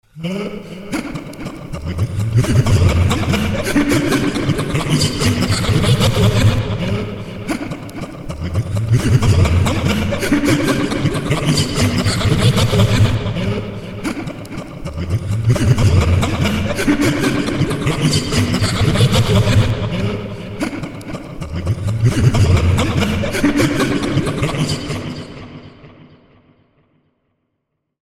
Weird Laughter Sound Effect Download: Instant Soundboard Button
1. Play instantly: Click the sound button above to play the Weird Laughter sound immediately in your browser.